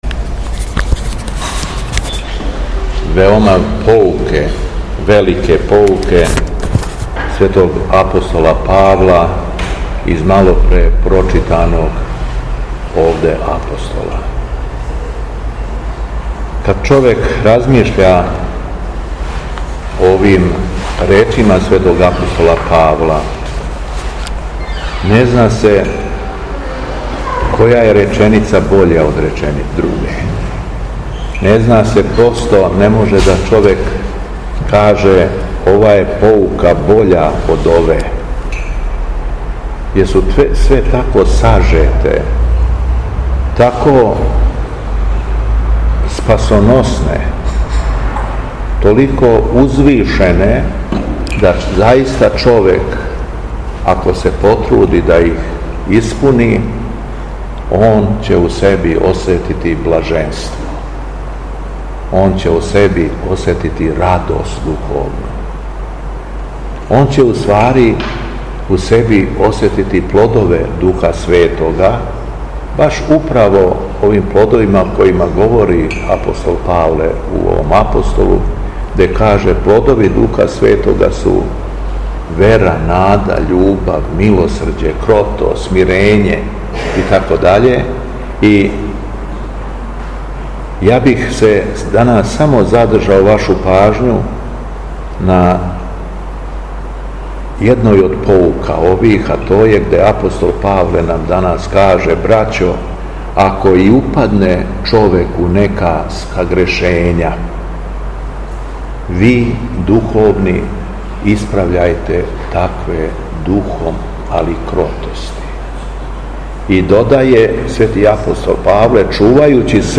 Беседа Његовог Високопреосвештенства Митрополита шумадијског г. Јована
Након прочитаног јеванђелског зачала Високопреосвећени се обратио верном народу надахнутом беседом: